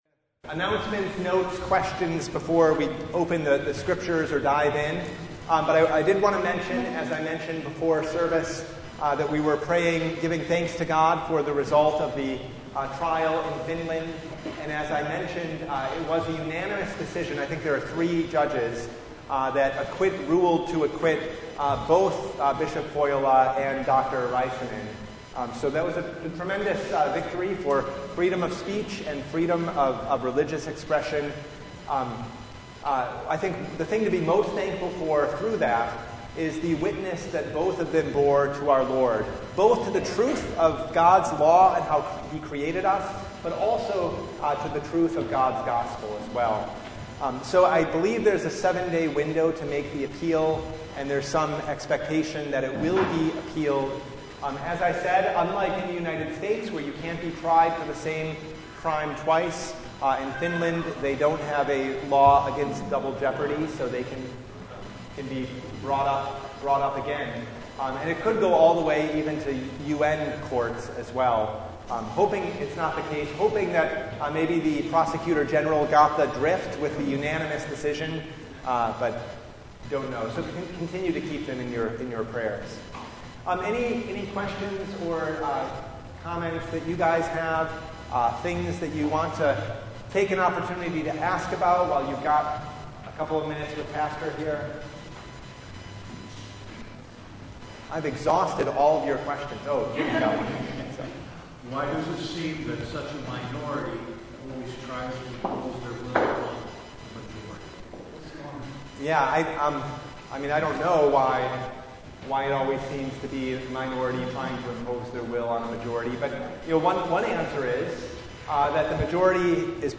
Passage: Luke 5:12-27 Service Type: Bible Study